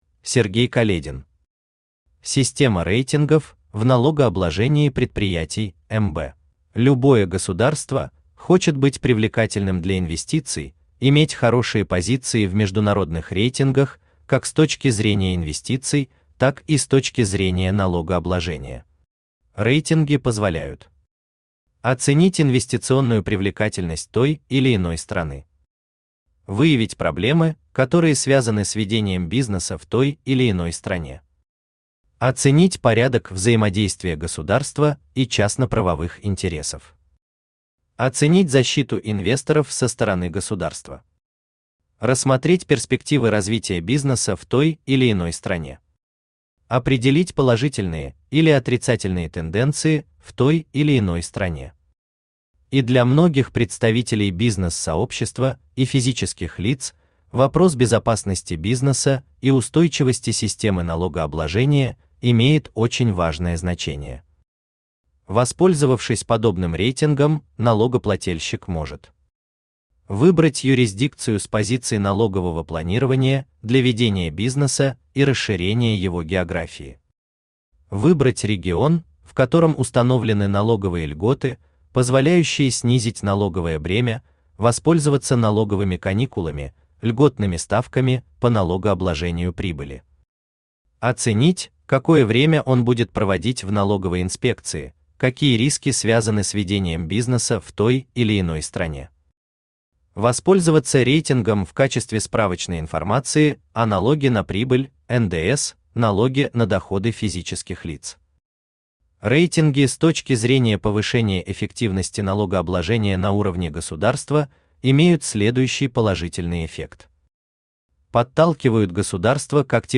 Аудиокнига Система рейтингов в налогообложении предприятий МБ | Библиотека аудиокниг
Aудиокнига Система рейтингов в налогообложении предприятий МБ Автор Сергей Каледин Читает аудиокнигу Авточтец ЛитРес.